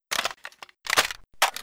m16_reload.wav